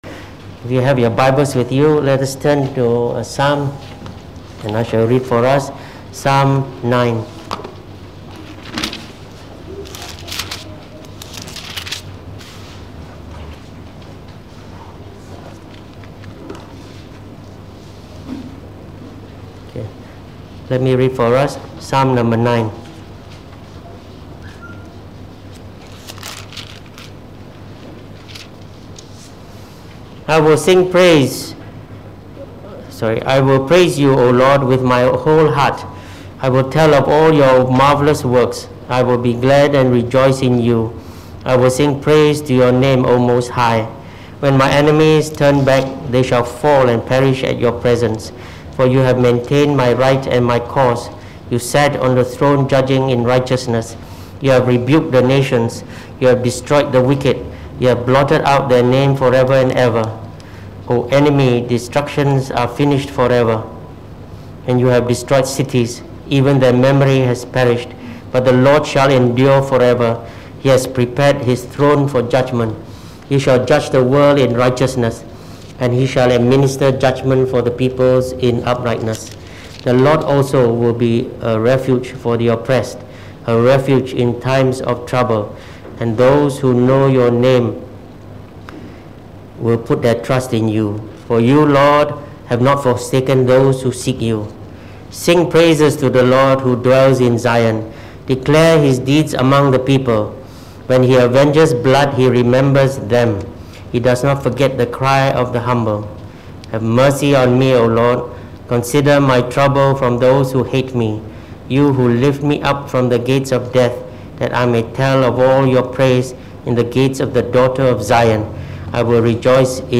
| Reformed Baptist Churches Malaysia